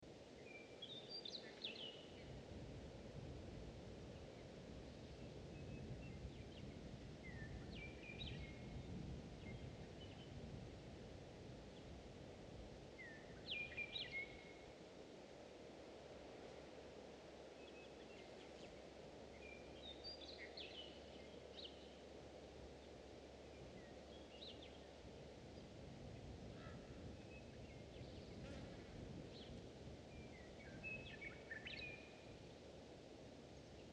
دانلود آهنگ جنگل 12 از افکت صوتی طبیعت و محیط
جلوه های صوتی
دانلود صدای جنگل 12 از ساعد نیوز با لینک مستقیم و کیفیت بالا